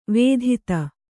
♪ vēdhita